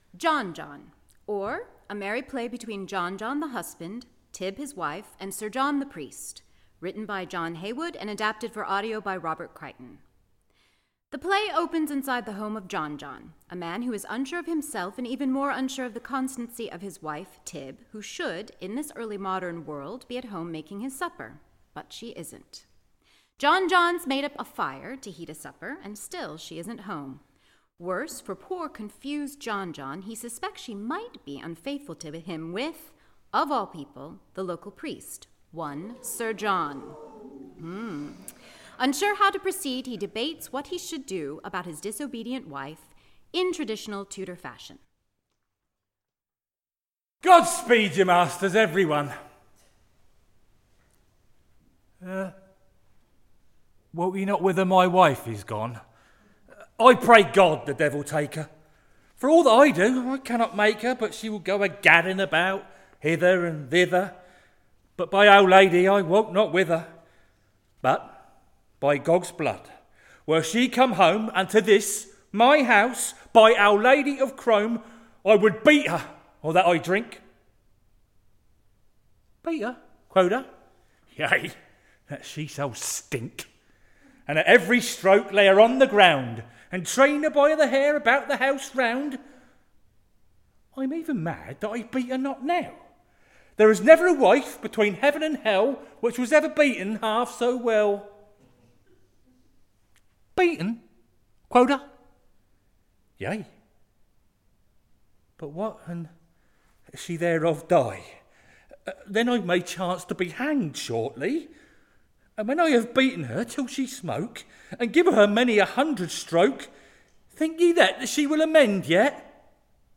Facebook Twitter Headliner Embed Embed Code See more options John John by John Heywood A Full Cast Audio Adaptation of 'A merry play between John John the husband, Tyb his wife, and Sir John the Priest' by John Heywood. John John believes his wife is having an affair with the local priest.
Recorded at the Quay Theatre